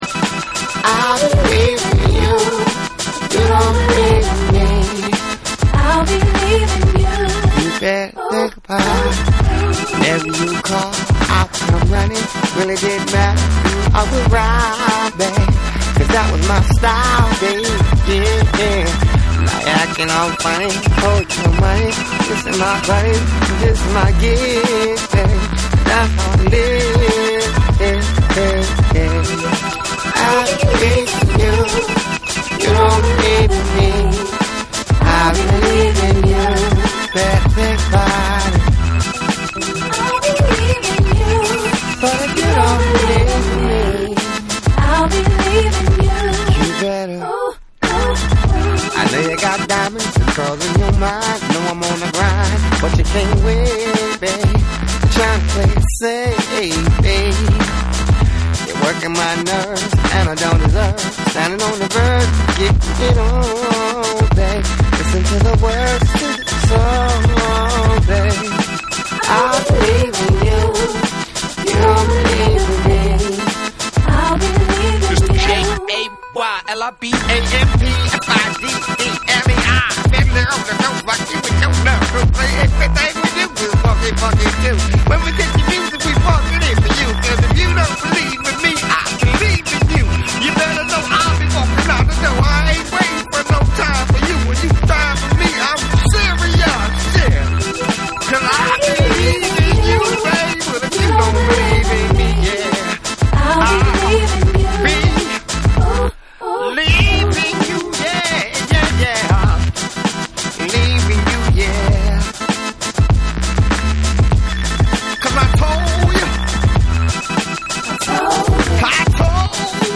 アーバンな雰囲気も漂う漆黒のエレクトリック・ファンク
TECHNO & HOUSE / DETROIT